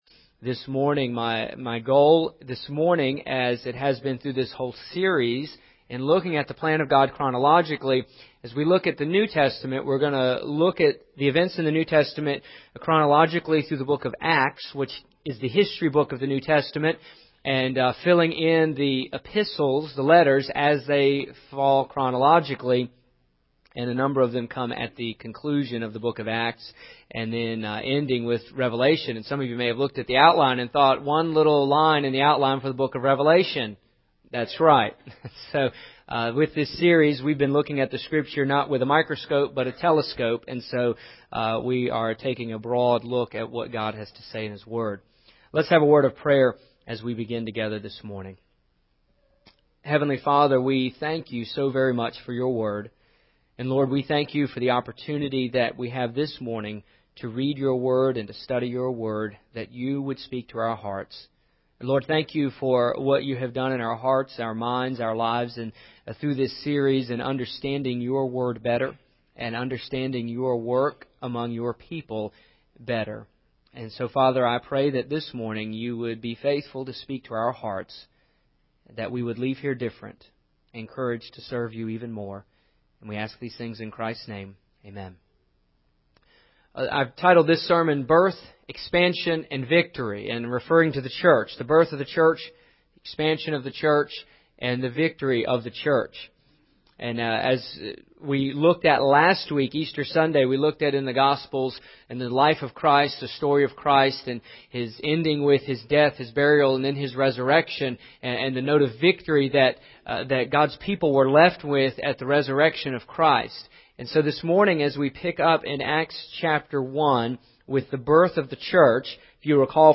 Here is the 5th part of my 5 part series preaching through the Bible.